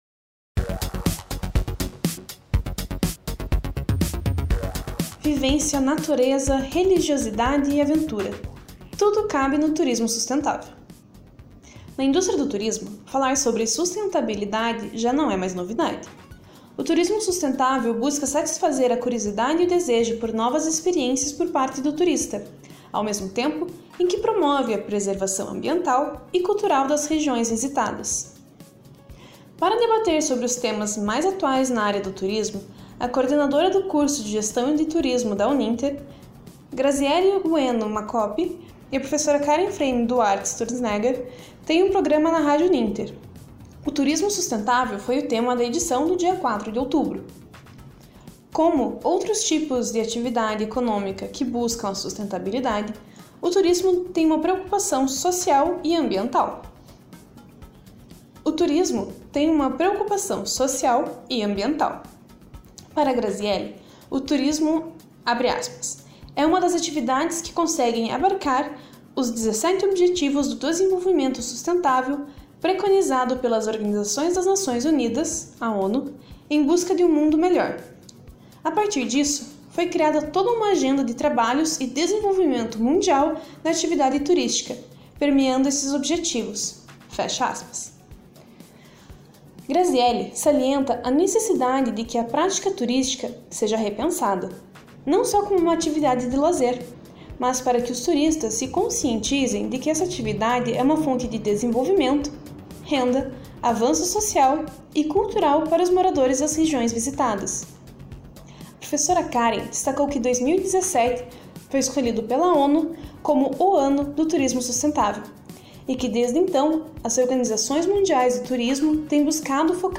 O programa Por Dentro do Turismo vai ao ar ao vivo todas as quartas- feiras às 10h30, na Rádio Uninter.